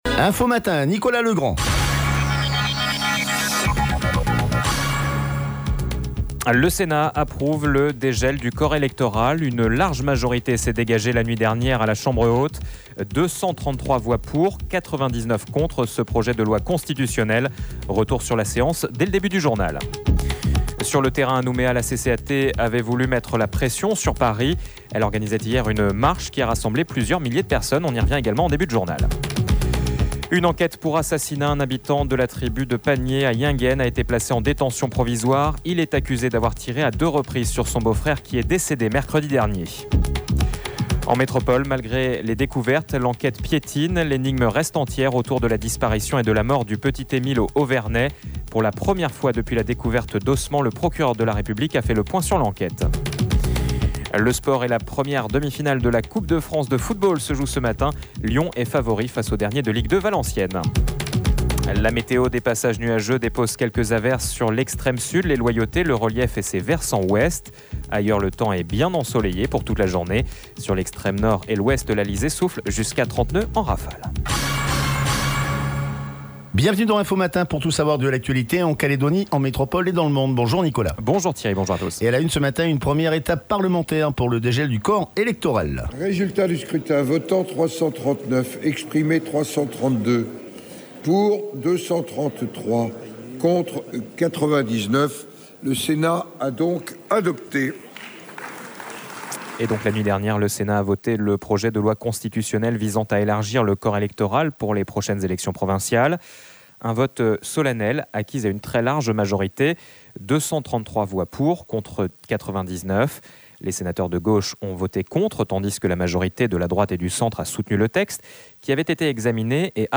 JOURNAL : INFO MATIN MERCREDI